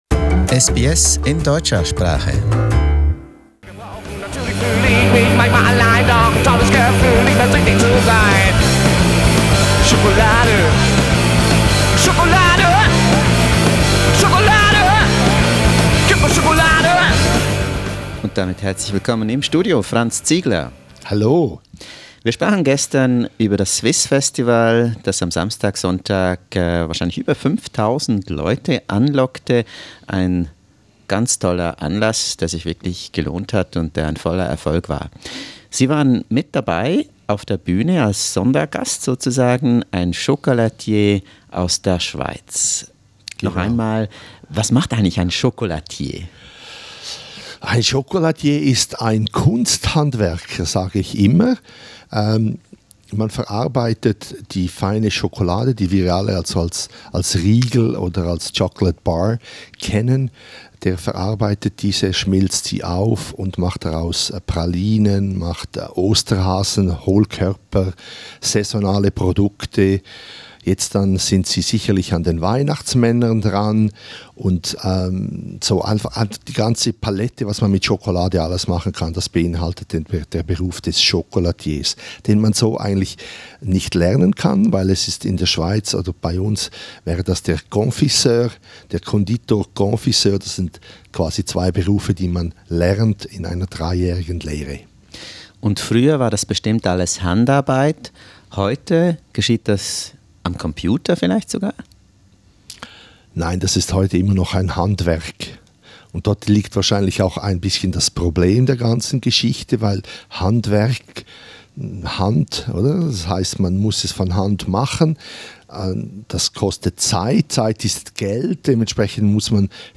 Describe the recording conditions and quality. At SBS Radio in Melbourne